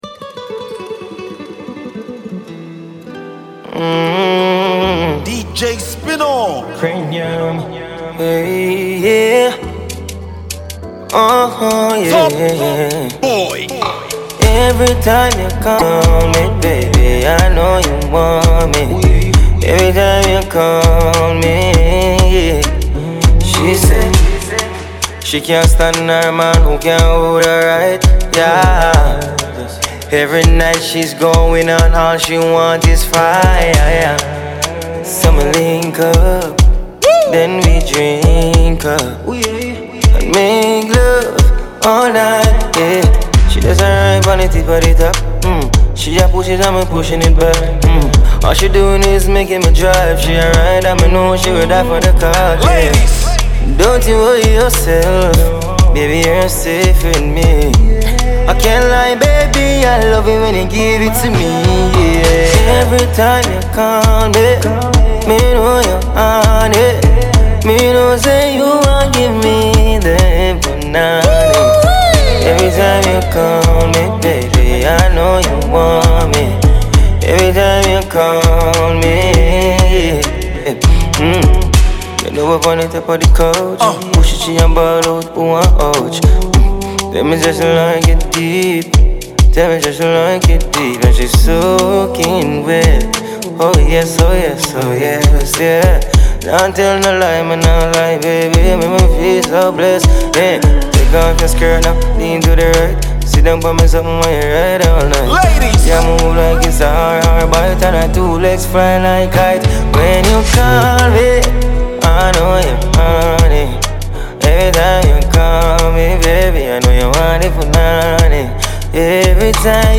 reggae-dancehall